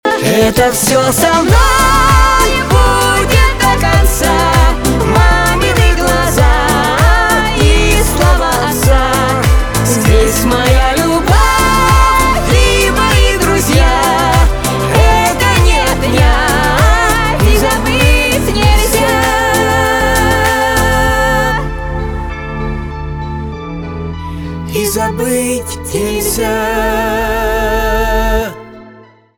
эстрада
патриотические
чувственные